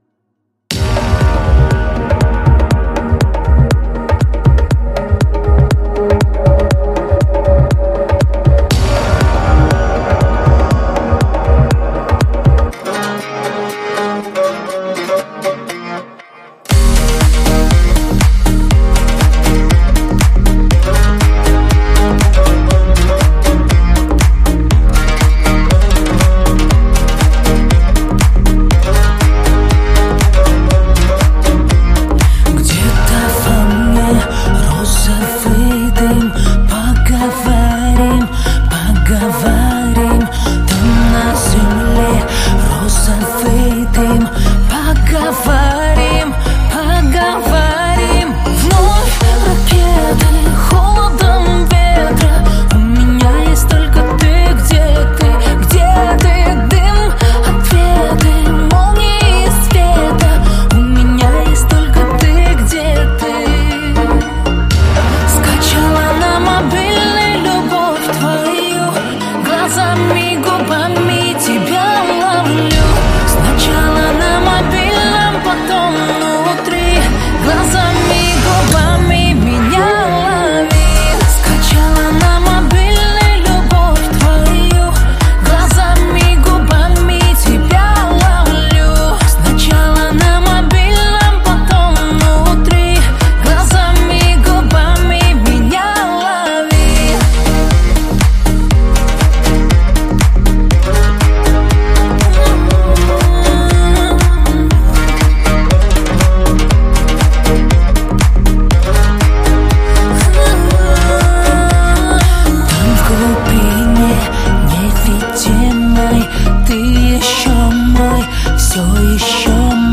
• Жанр: New Rus / Русские песни